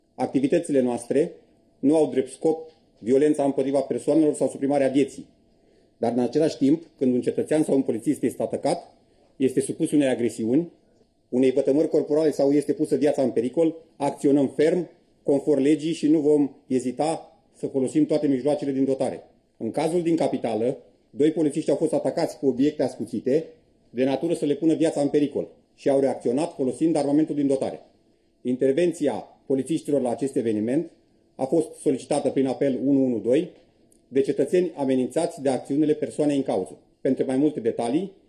Secretarul de stat pentru de ordine şi siguranţă publică în Ministerul Internelor, Bogdan Despescu a declarat că o asemenea intervenţie se face gradual, în funcţie de situaţia din teren.